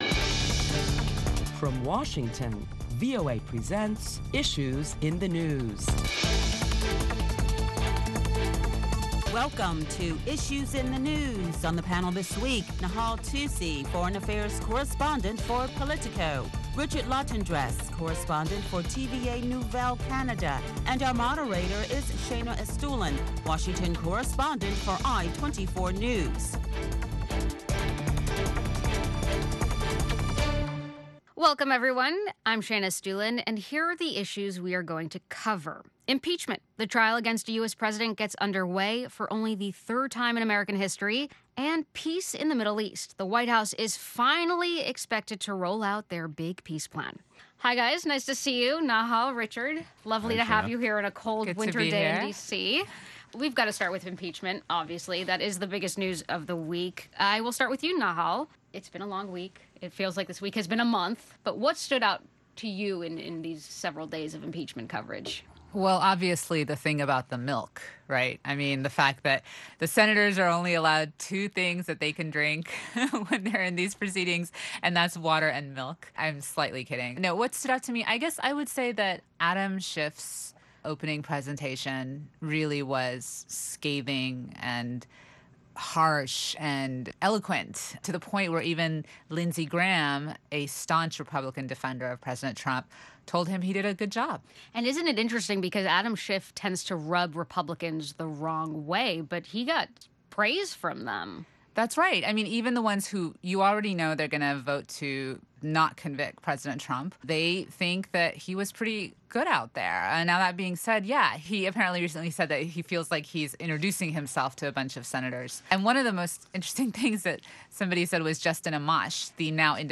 Listen to a panel of prominent Washington journalists as they deliberate the latest top stories of the week that include the Democrats making impassioned pleas to the Senate for the removal of President Trump from office.